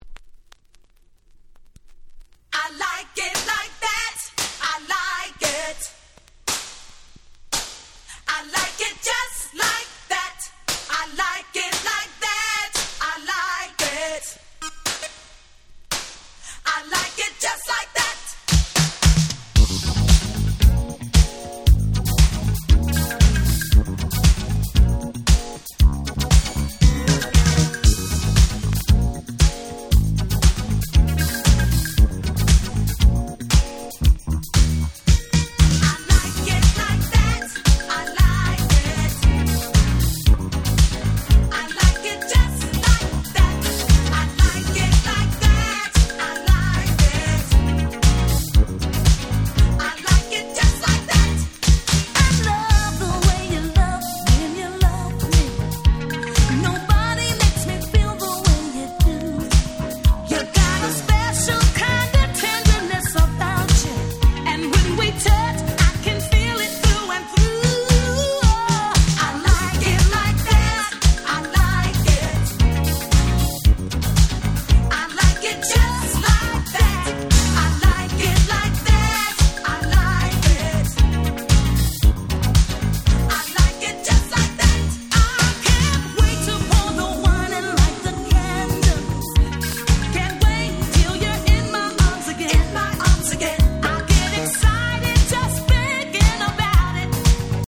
3曲共に言わずと知れた最高のDisco / Boogie !!